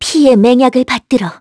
Lewsia_A-Vox_Skill5-1_kr.wav